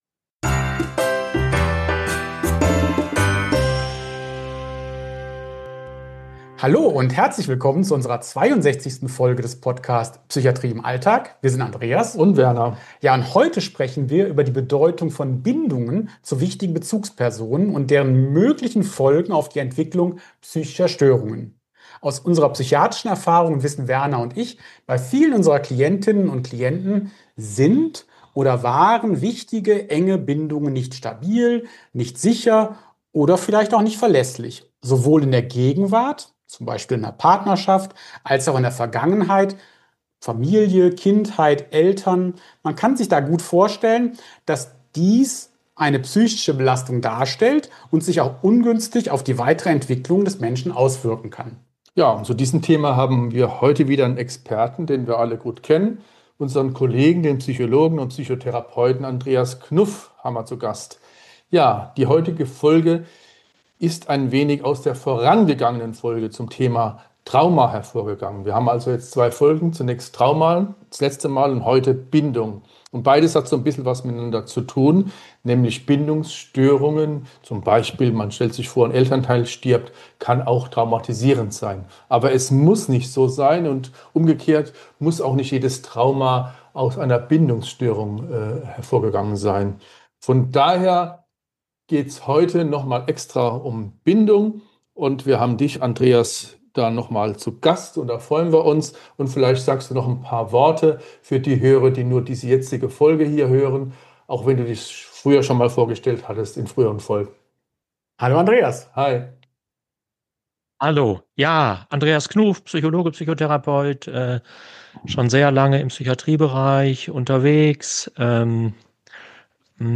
Die drei diskutieren, wie sich Fachpersonen mit bindungssensibler Arbeit auseinandersetzen sollten und wie Teams entsprechende Kompetenzen entwickeln können.